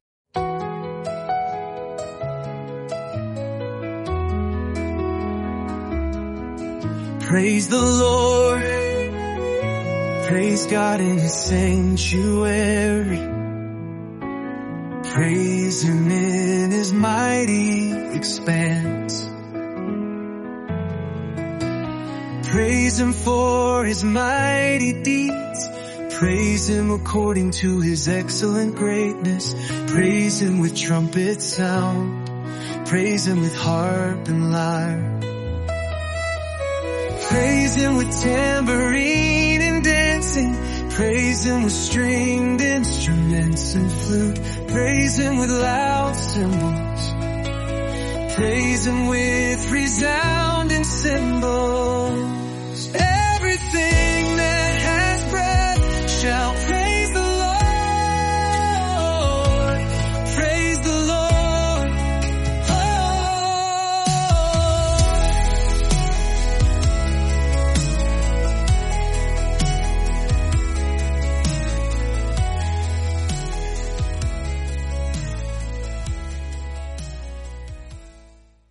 Immerse yourself in the prayers, praises, and wisdom of Psalms in just 30 days through word-for-word Scripture songs.